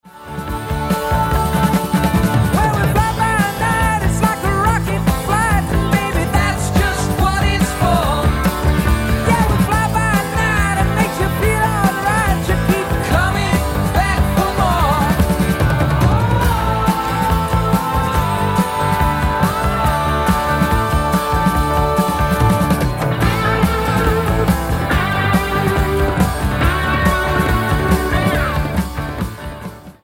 bass, vocals
drums, percussion
guitar, vocals, solina, piano
Album Notes: Recorded at Can-Base Studios, Vancouver, Canada